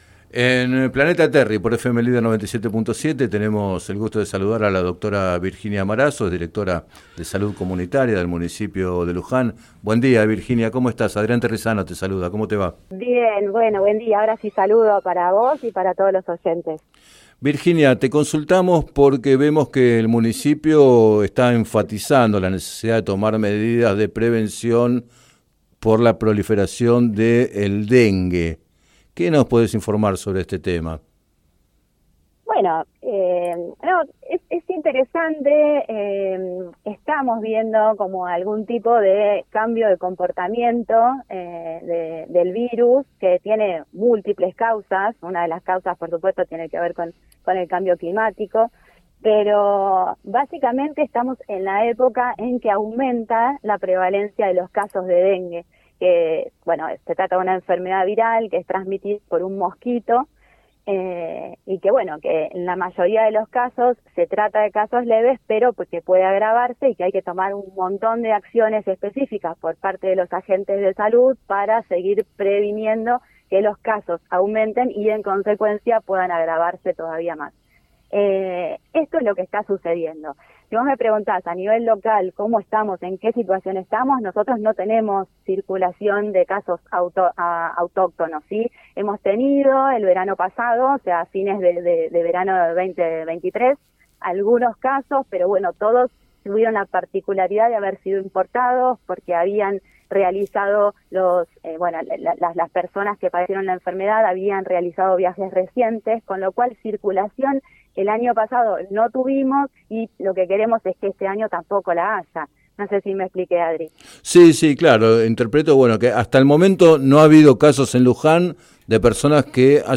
En declaraciones al programa Planeta Terri de FM Líder 97.7